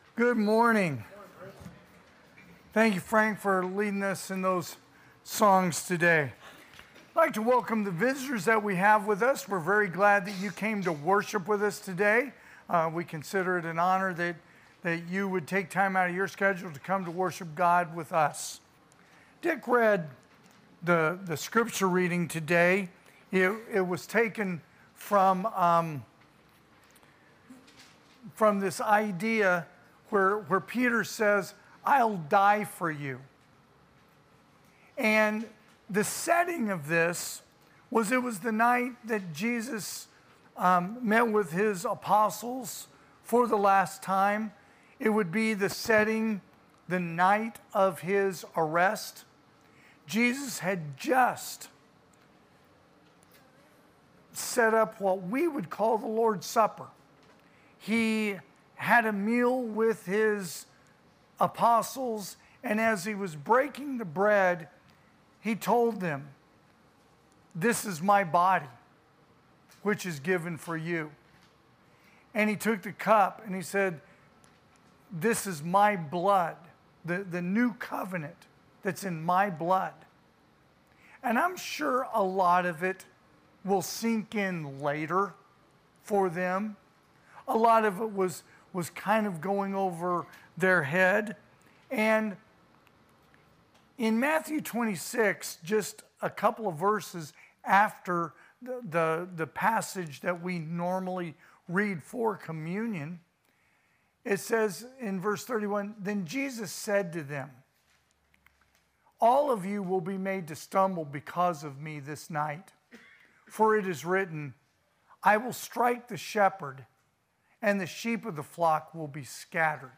2025 (AM Worship) "I'll Die For You"
Sermons